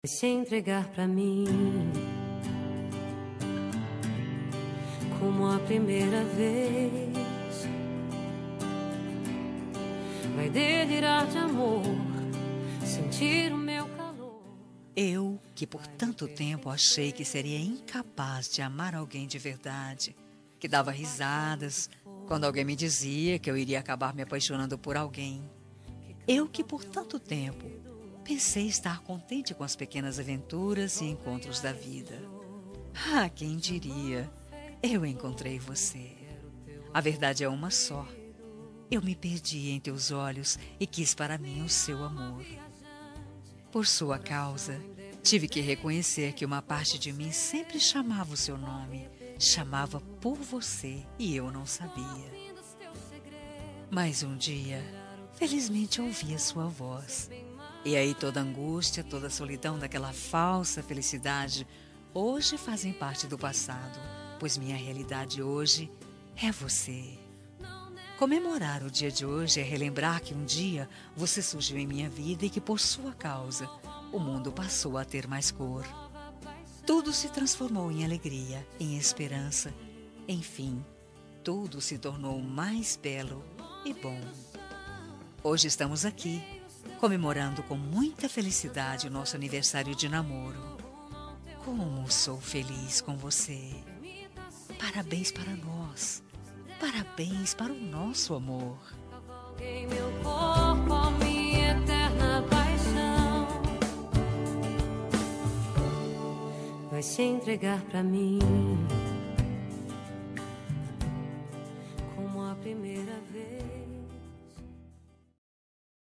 Telemensagem Aniversário de Namoro – Voz Feminina – Cód: 80928